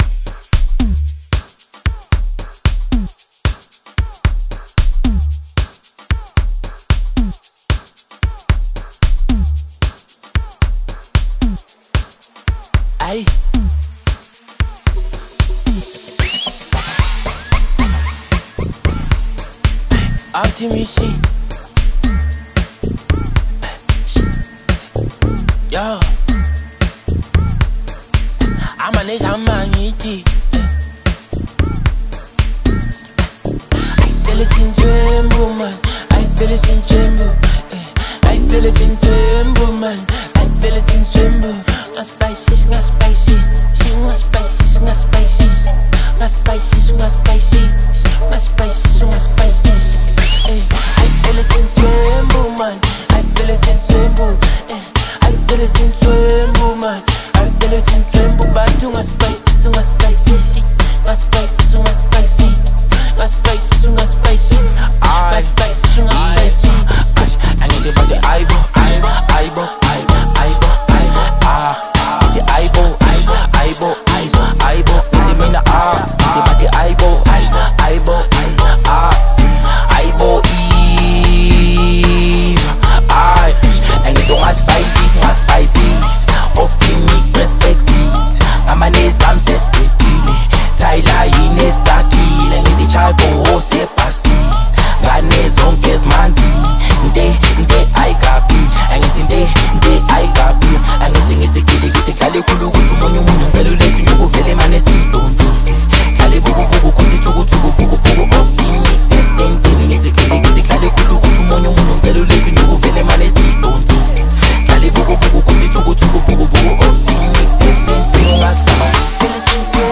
signature basslines and intricate instrumentation